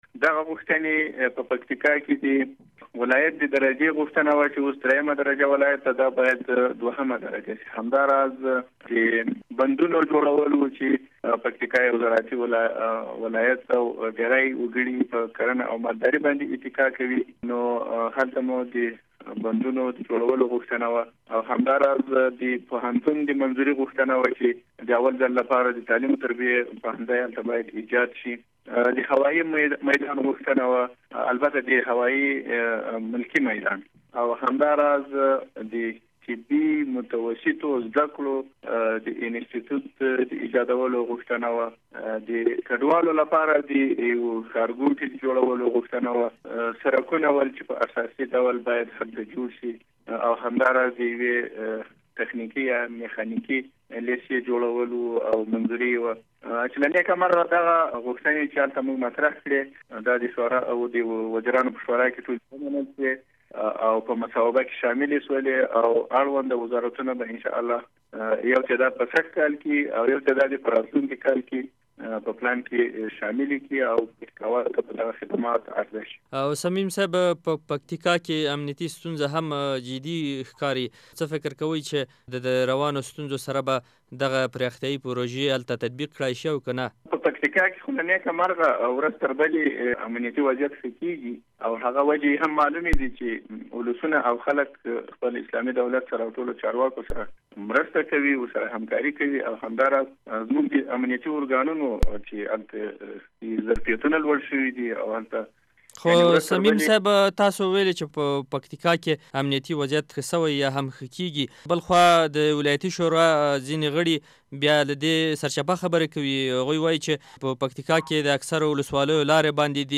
د پکتیکا له والي سره مرکه